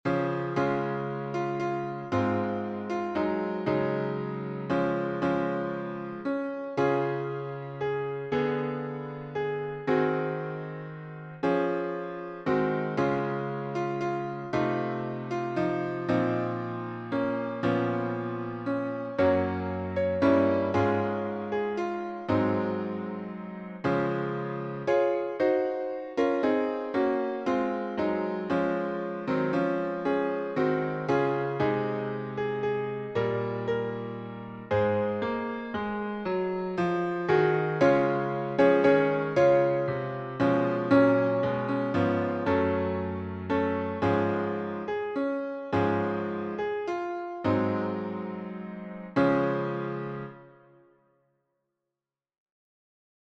H. Gabriel (1856-1932)Key signature: D flat major (5 flats)Time signature: 6/4Meter: 10.7.10.9 with refrainPublic Domain1.